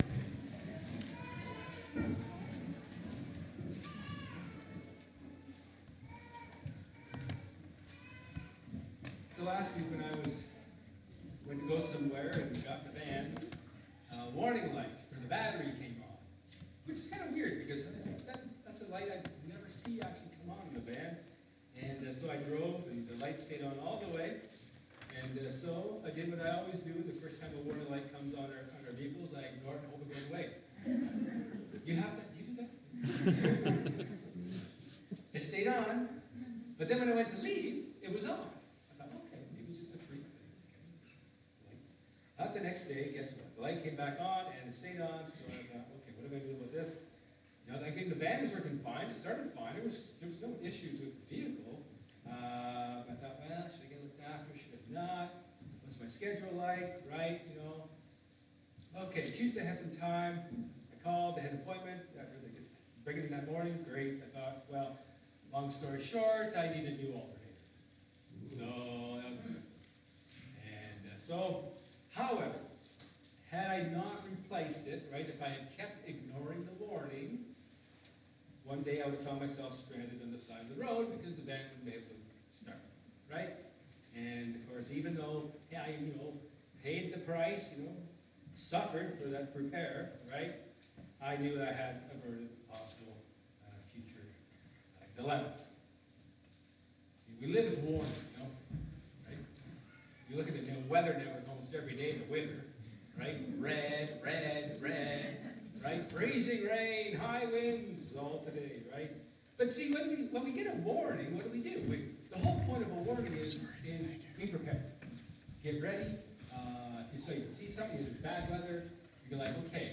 Micah 4 Service Type: Sermon